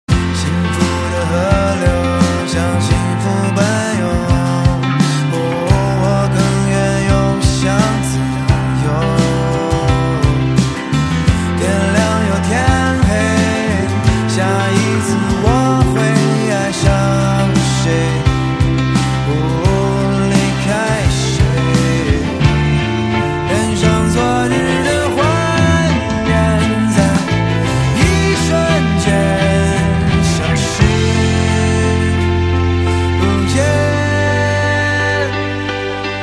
M4R铃声, MP3铃声, 华语歌曲 115 首发日期：2018-05-14 20:17 星期一